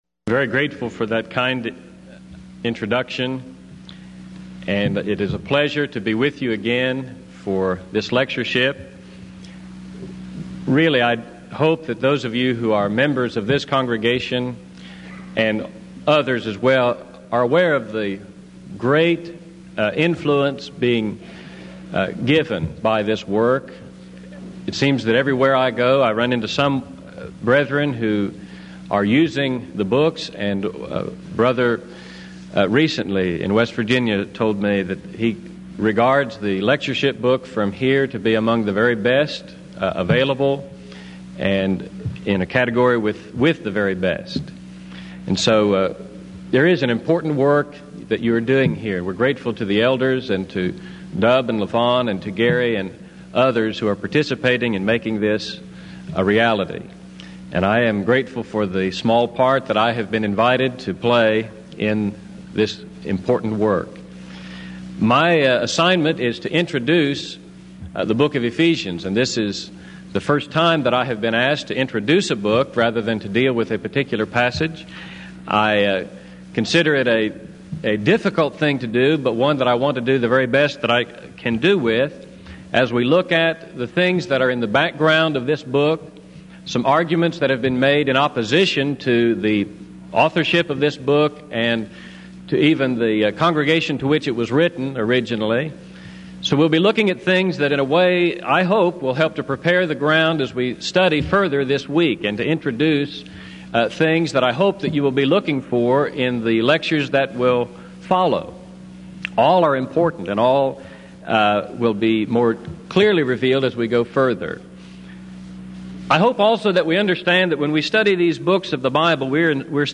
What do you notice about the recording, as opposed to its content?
Event: 16th Annual Denton Lectures